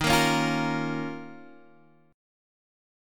D#7sus2 chord